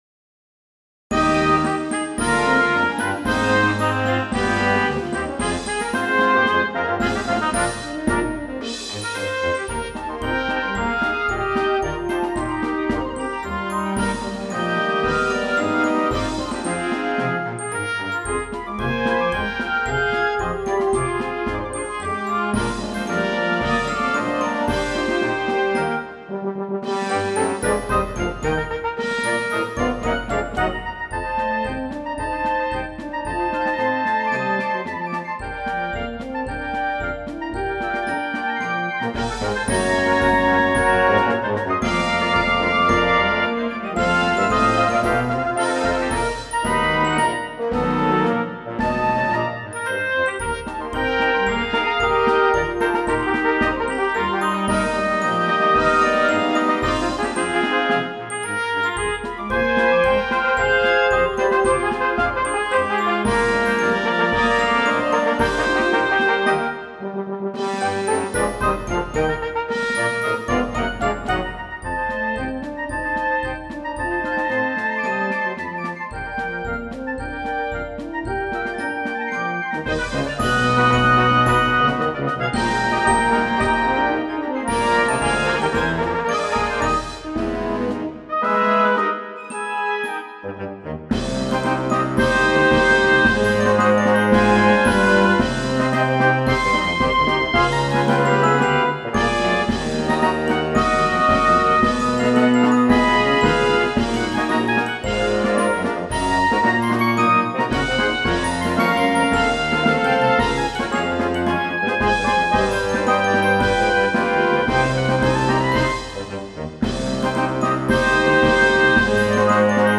Blasorchester